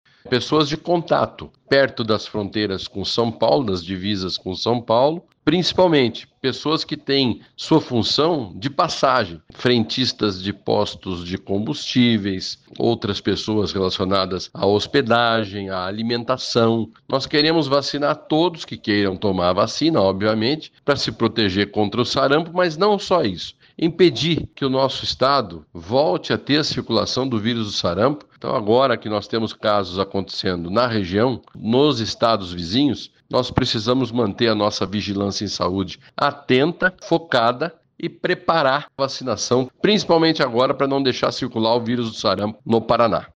Com as confirmações em estados próximos, a Sesa passou a intensificar a vacinação, principalmente nos municípios que fazem divisa com São Paulo, como alertou Beto Preto.